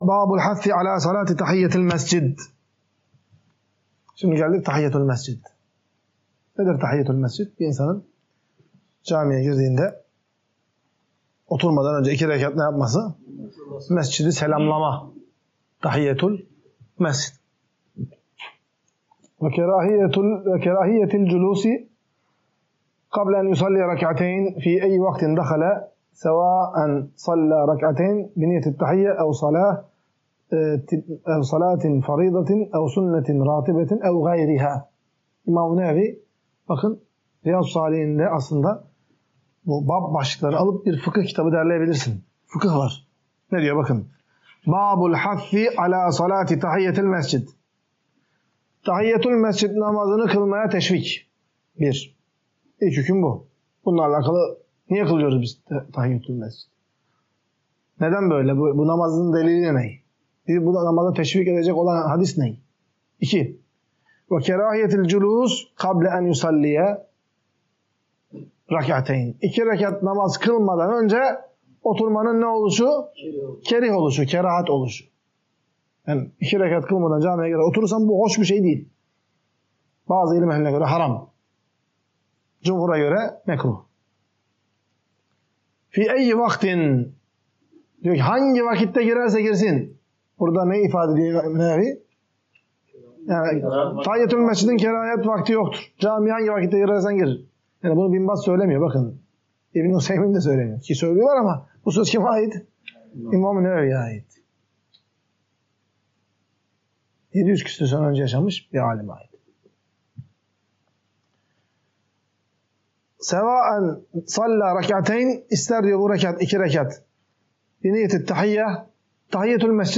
Ders.mp3